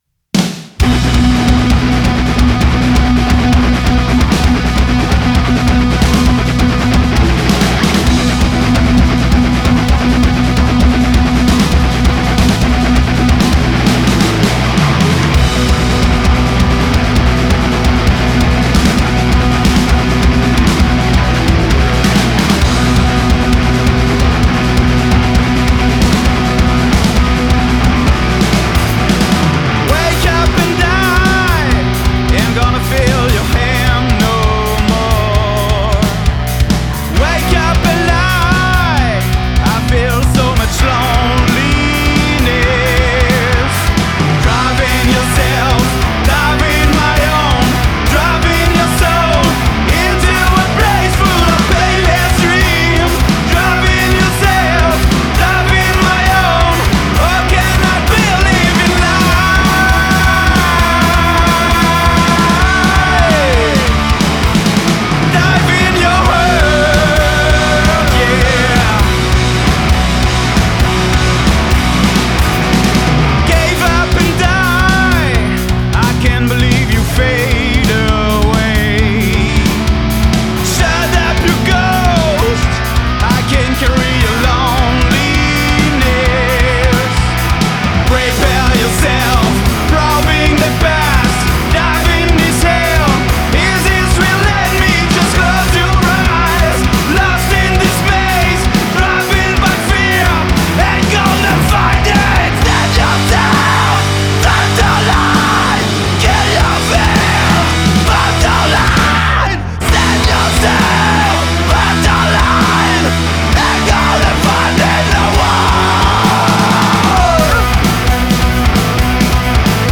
combo rock bordelais